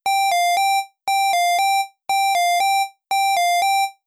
potato_timer.wav